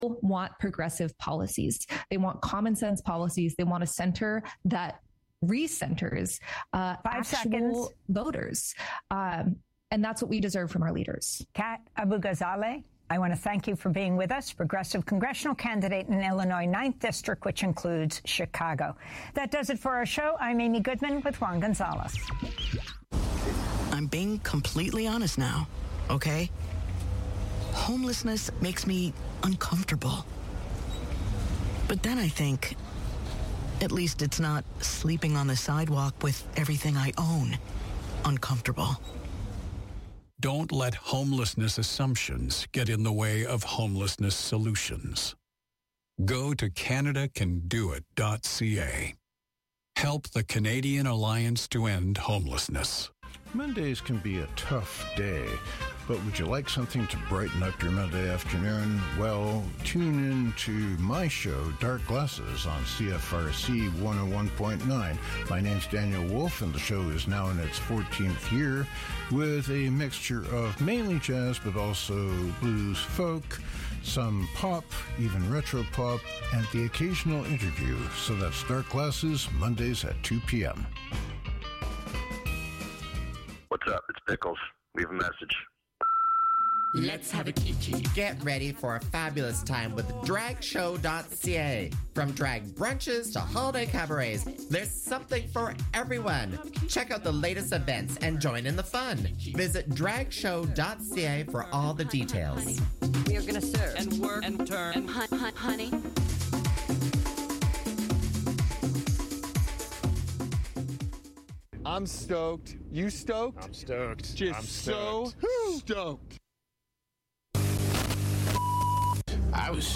Featuring a mix of indie, alt rock and new wave music, this show currates themed weekly playlists to keep the listeners on their toes.